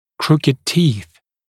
[‘krukɪd tiːθ][‘крукид ти:с]кривые зубы (разг.)